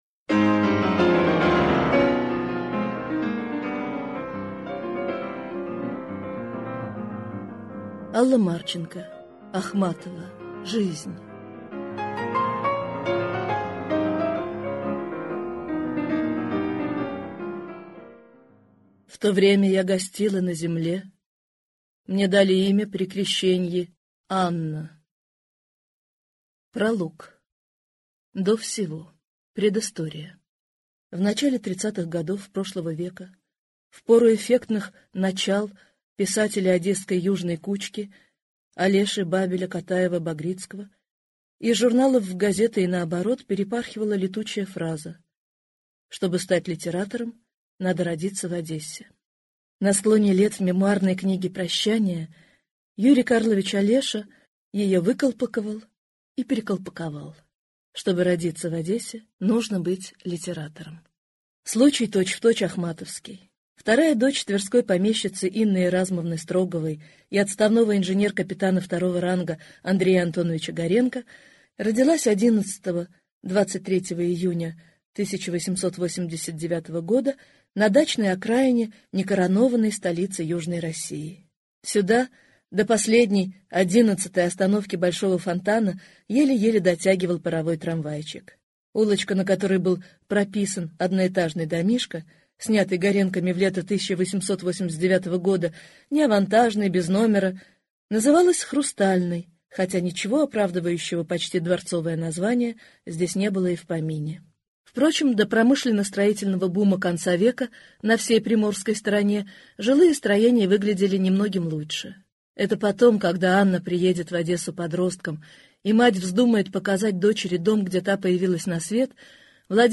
Аудиокнига Ахматова: жизнь | Библиотека аудиокниг